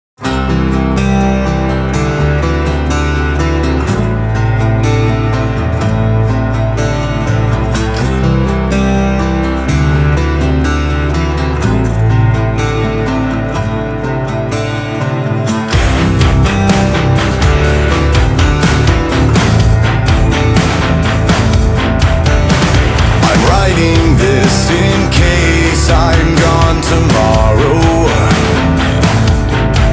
• Metal